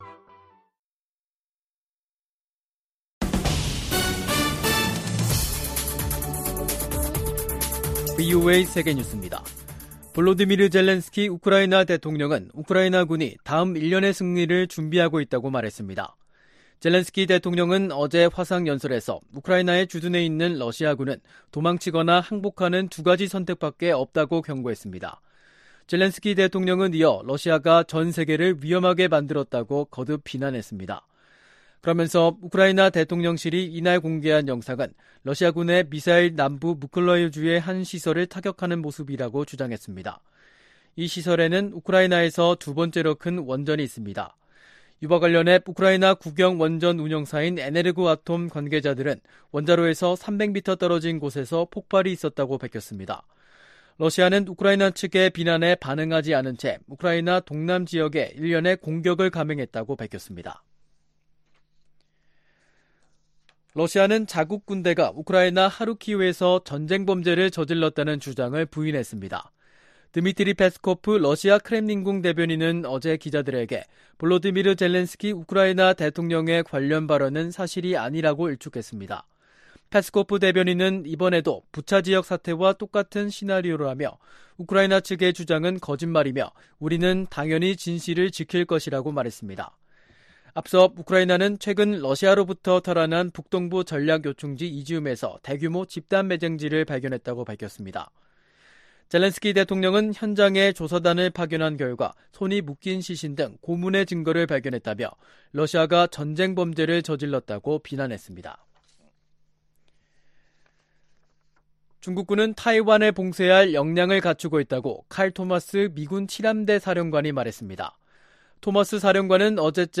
VOA 한국어 간판 뉴스 프로그램 '뉴스 투데이', 2022년 9월 20일 2부 방송입니다.